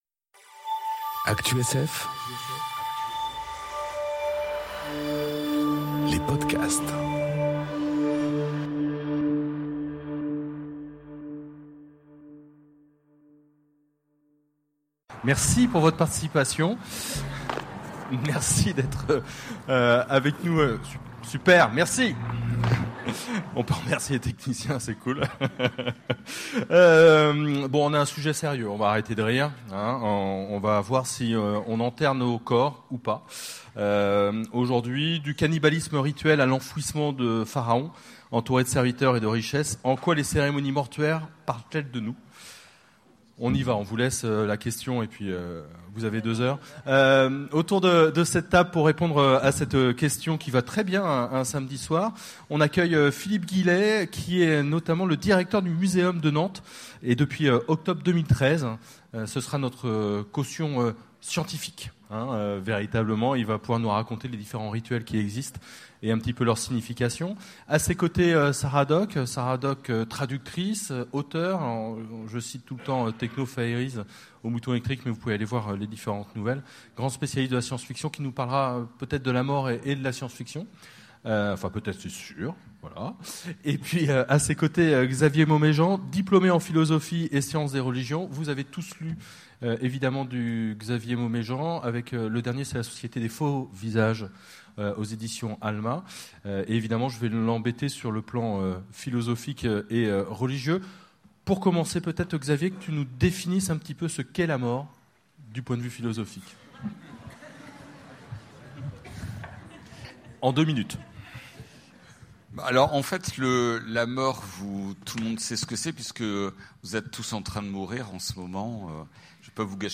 Conférence Enterrer son corps, ou pas ? enregistrée aux Utopiales 2018